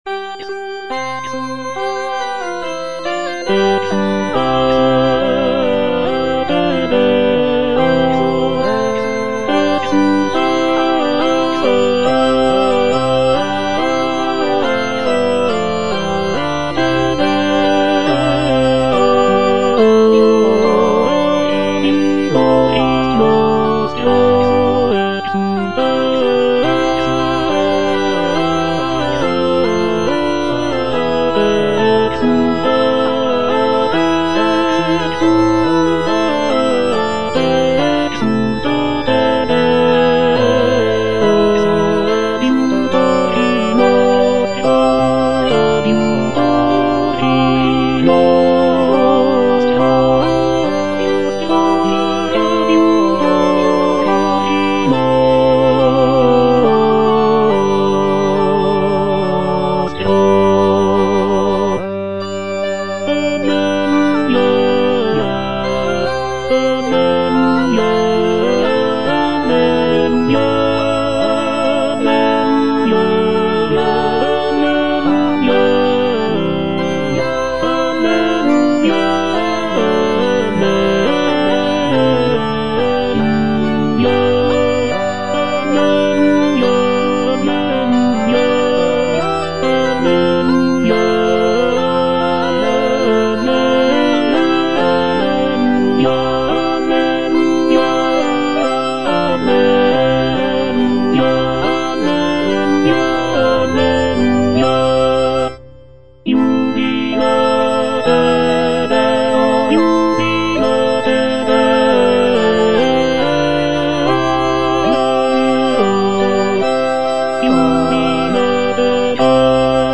Tenor (Emphasised voice and other voices) Ads stop
sacred choral work